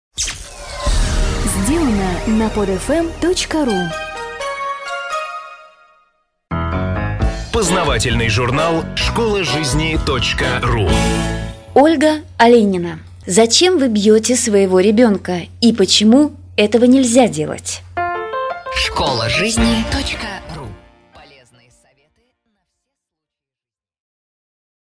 Аудиокнига Зачем вы бьёте своего ребёнка и почему этого нельзя делать?